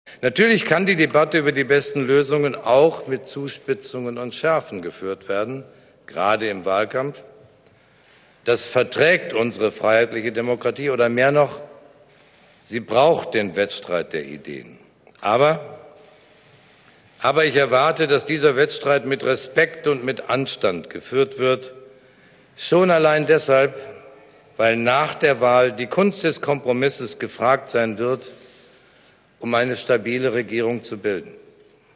In einer Pressekonferenz begründete Steinmeier seine Entscheidung damit, dass es „in schwierigen Zeiten wie jetzt“ eine handlungsfähige Regierung und verlässliche Mehrheiten im Parlament“ brauche, um Stabilität zu gewährleisten.